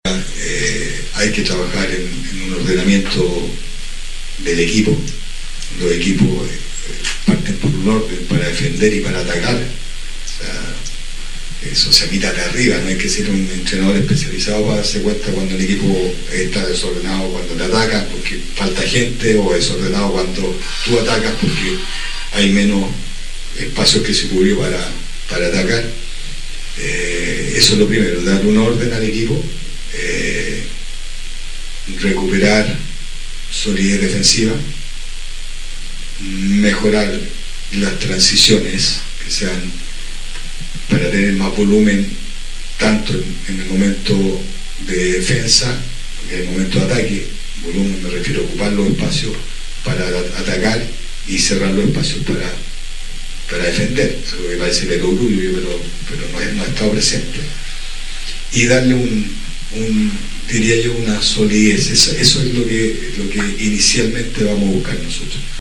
En conferencia de prensa, el técnico envió un saludo a los hinchas, resaltando la importancia de la comunicación con la comunidad y el sentido de pertenencia en la ciudad nortino.